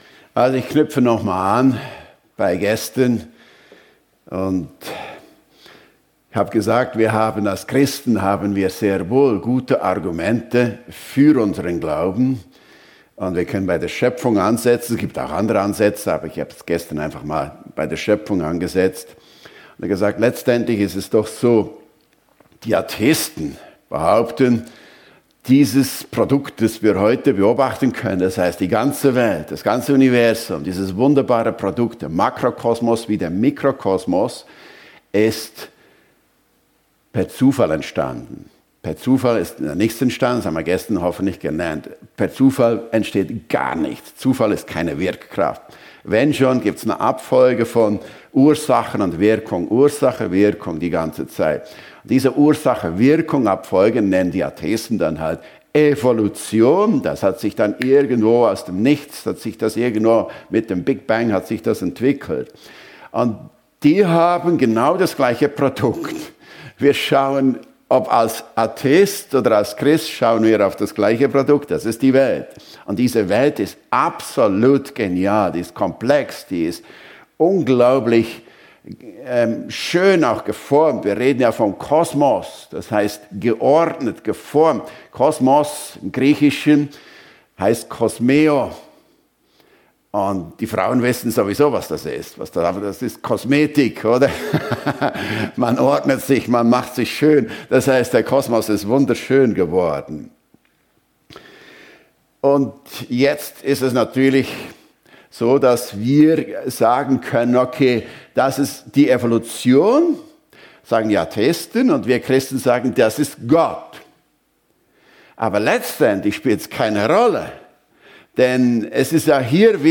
Bibeltage - Wer ist Gott? Einheit in Vielfalt. ~ FEG Sumiswald - Predigten Podcast